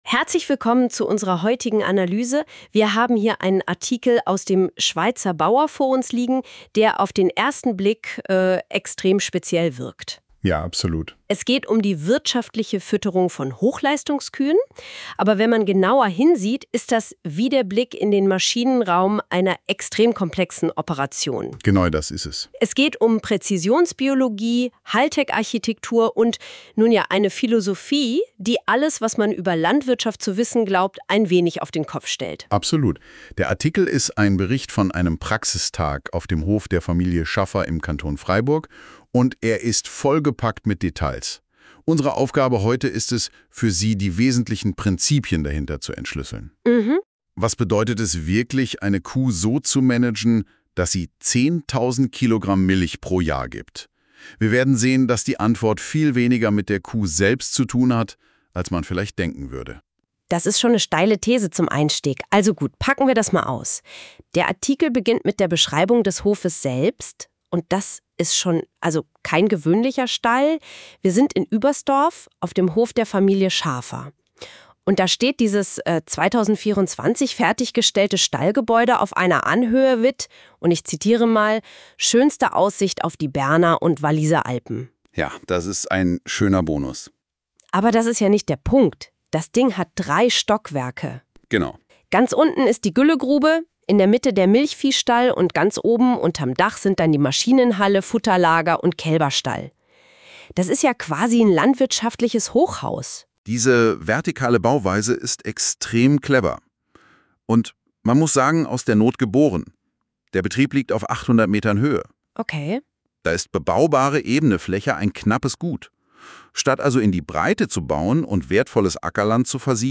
Podcast über den 6. Eurofarm-Praxismilchviehtag: (erstellt mit Hilfe mit AI) Dein Browser unterstützt das Audio-Element nicht.